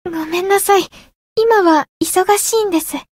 灵魂潮汐-梦咲音月-问候-情人节（相伴语音）.ogg